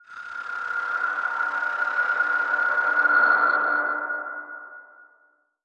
8 bits Elements
Magic Demo